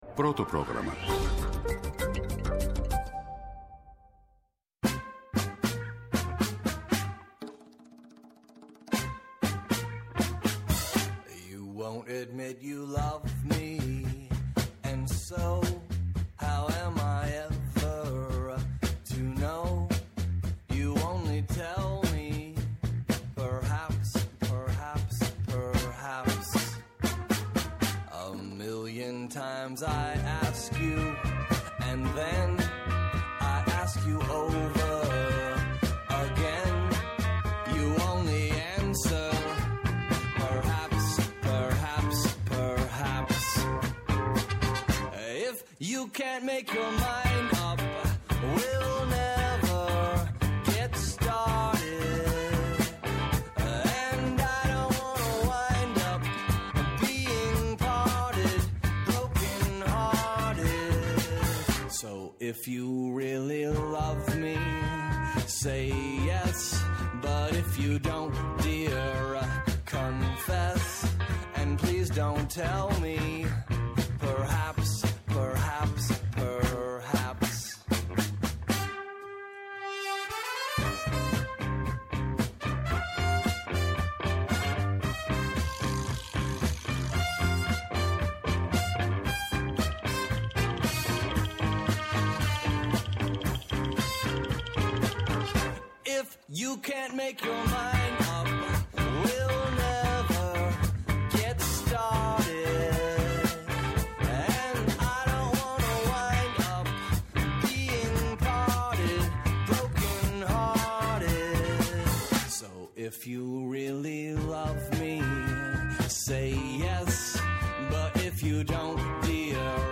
-Ο Δήμαρχος Σιθωνίας, Γιάννης Μαλλίνης
-Ο Γιώργος Τσαπάκος, Αντιπεριφερειάρχης Πολιτικής Προστασίας Κρήτης